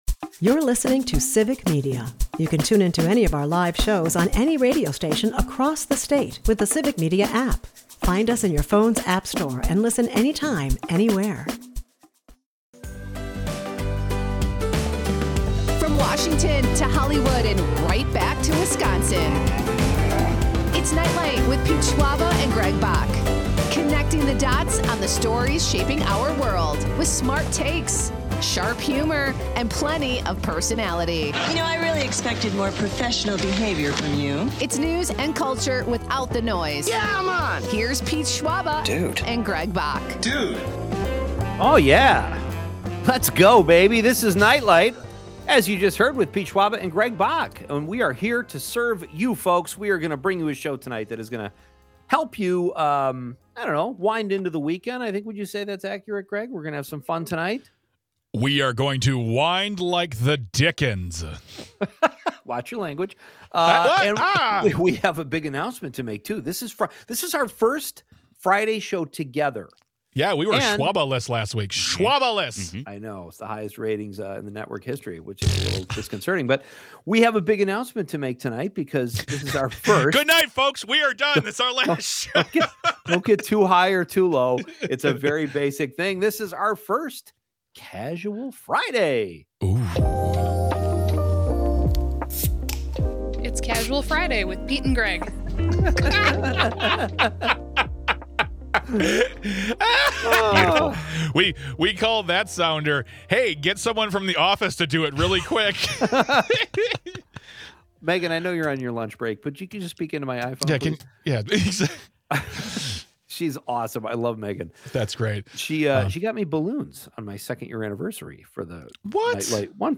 Talking with Jason Scheff, musician from the band Chicago (Hour 1) - Civic Media